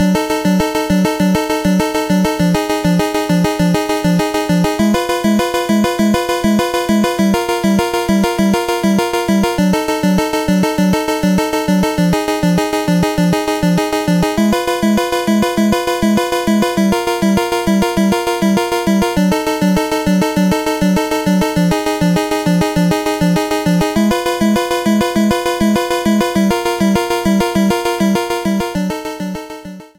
Clipped to 30 seconds and applied fade-out.